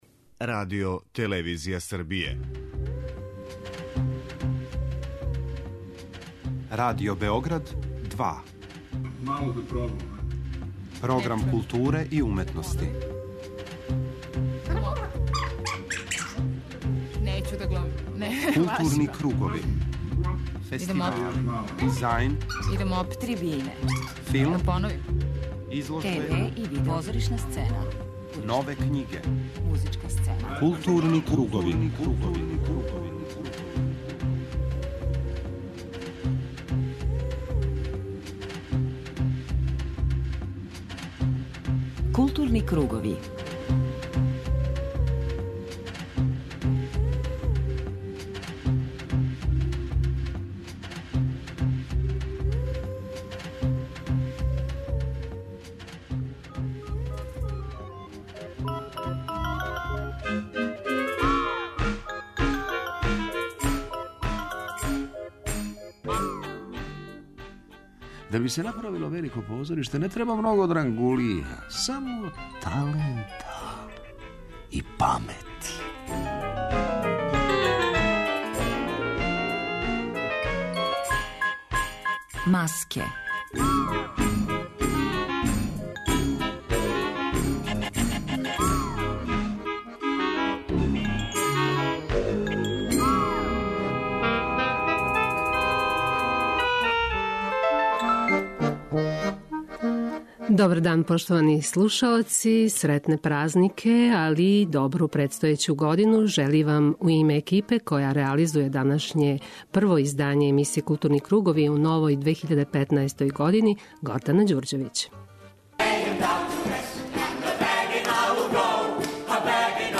У данашњем специјалу издвојили смо неке од најзначајнијих разговора из 2014. тако да ћете између осталих, чути интервју са редитељем Егоном Савином и глумцем, Драганом Петровићем Пелетом.